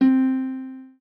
lyre_c.ogg